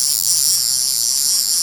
BP_14_SFX_Rope_Reeling.ogg